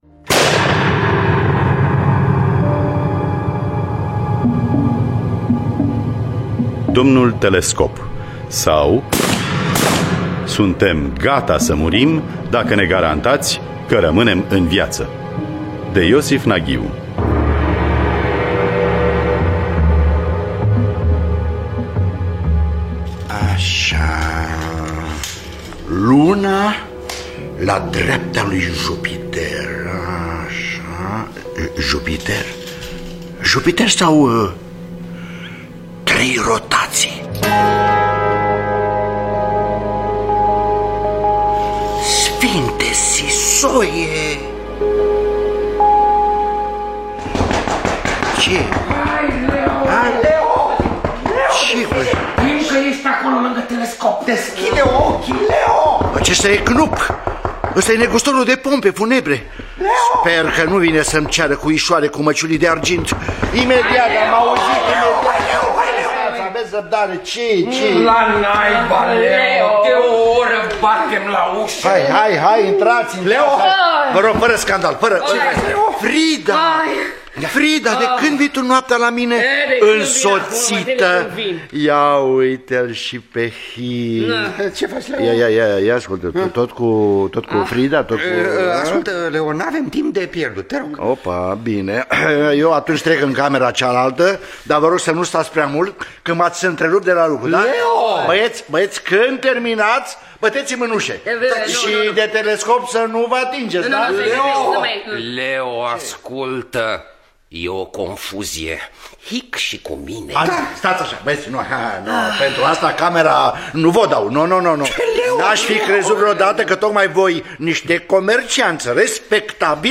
Domnul Telescop sau Suntem gata să murim dacă ne garantați că rămânem în viață de Iosif Naghiu – Teatru Radiofonic Online